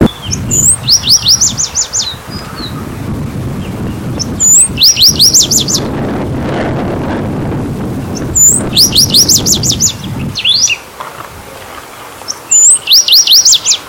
Cachilo Corona Castaña (Rhynchospiza strigiceps)
Nombre en inglés: Chaco Sparrow
Fase de la vida: Adulto
Provincia / Departamento: San Luis
Condición: Silvestre
Certeza: Fotografiada, Vocalización Grabada
cachilo-corona-castana-san-luis1.mp3